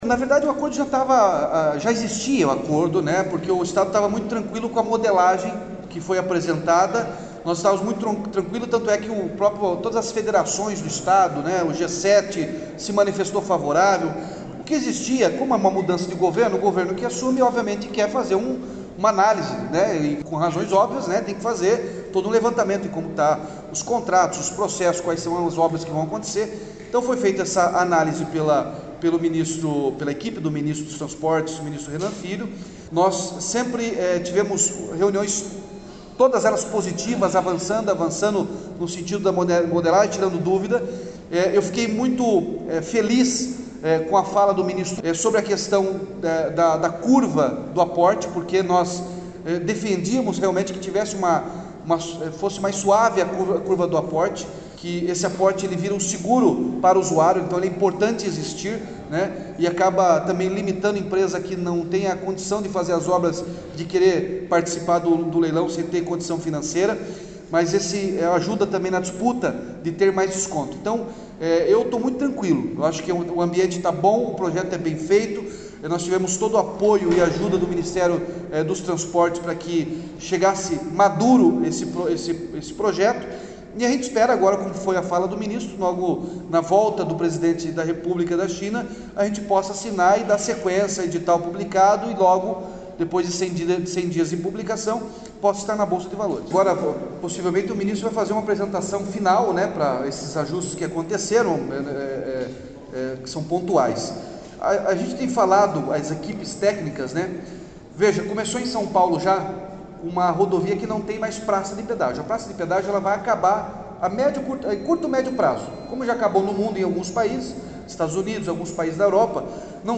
Sonora do governador Ratinho Junior sobre confirmação do aporte para descontos maiores que 18% do pedágio | Governo do Estado do Paraná